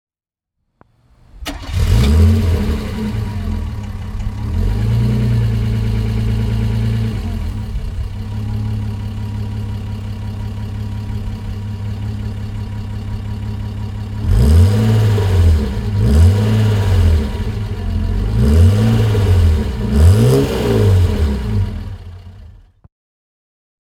Mercedes-Benz 280 SL (1969) - Starten und Leerlauf